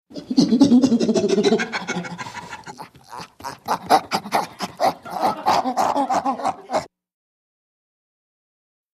Chimps | Sneak On The Lot
ANIMALS WILD: Chimpanzee excited voice over callusing.